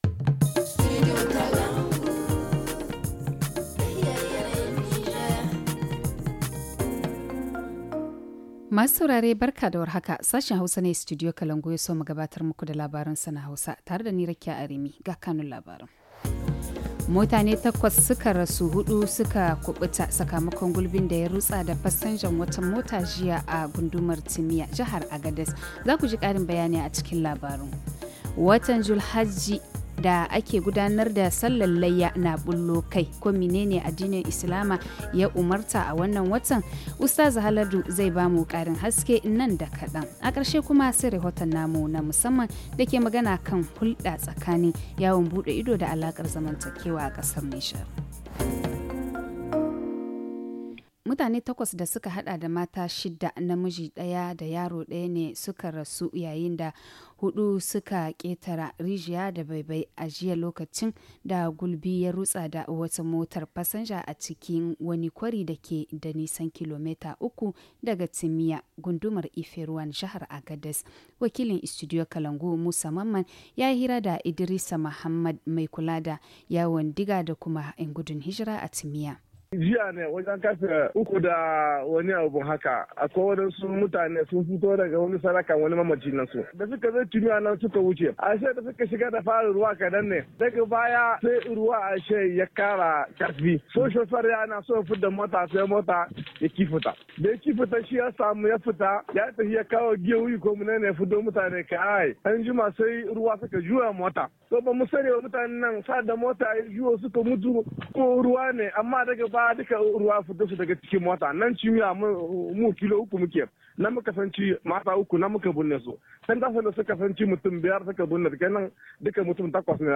Le journal du 09 juillet 2021 - Studio Kalangou - Au rythme du Niger